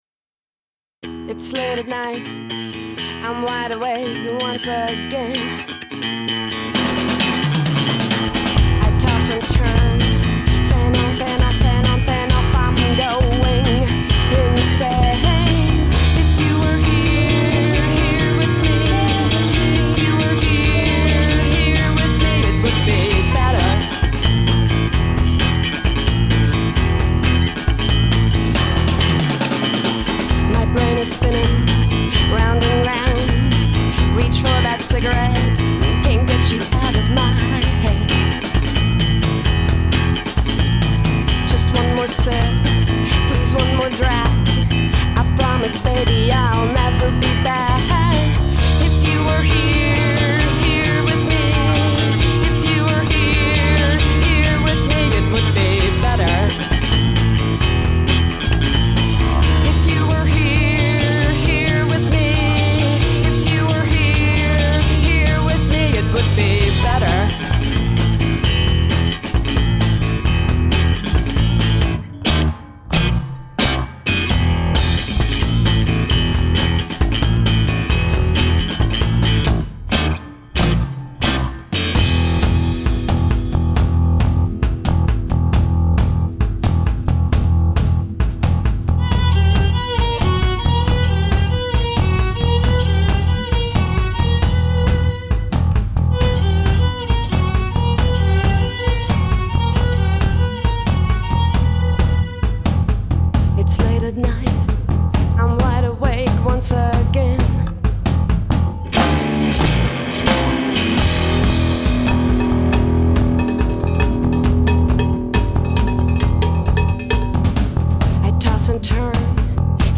It was a 4 piece all female band, except for me.